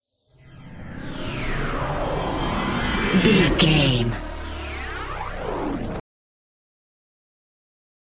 Sound Effects
Atonal
ominous
dark
eerie
synthesiser
ambience
pads